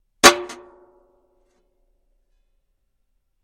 Звуки сковородки
Звук удара сковородой по телу